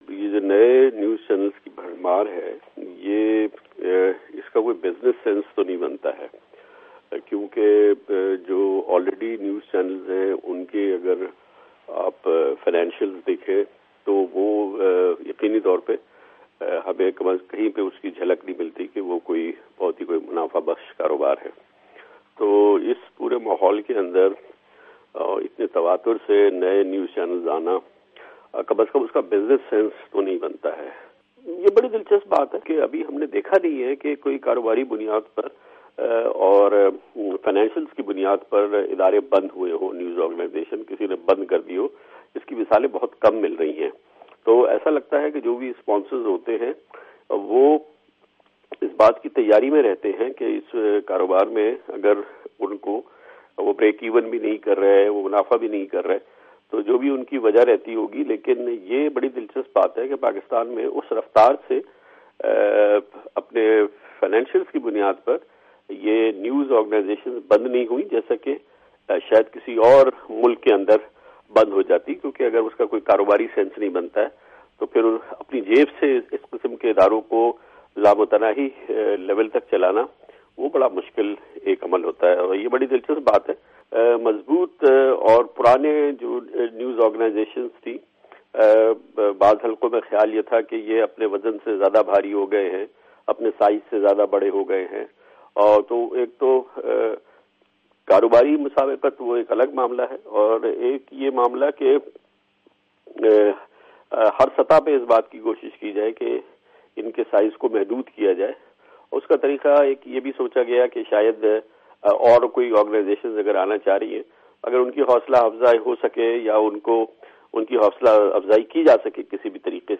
Kamran Khan Interview